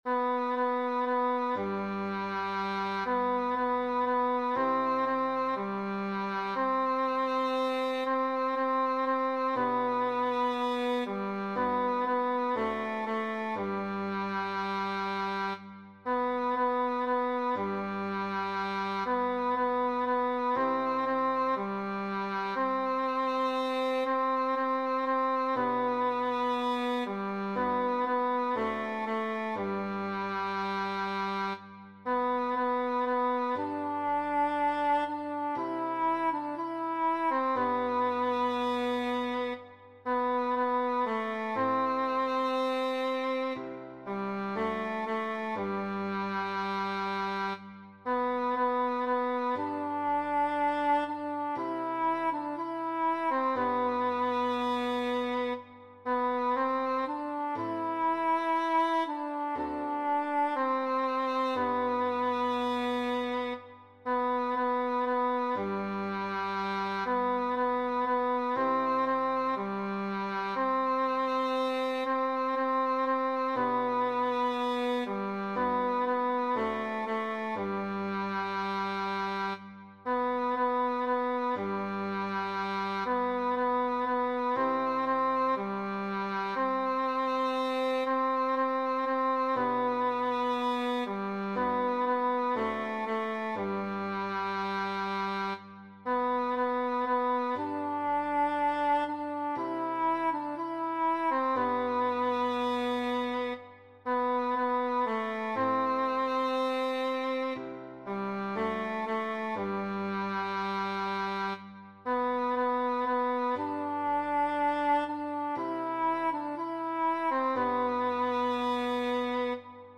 This is due to the fact that the melody is set in G major, which makes the second voice quite low in pitch.
I've attached a simple recording of the second voice as a mp3.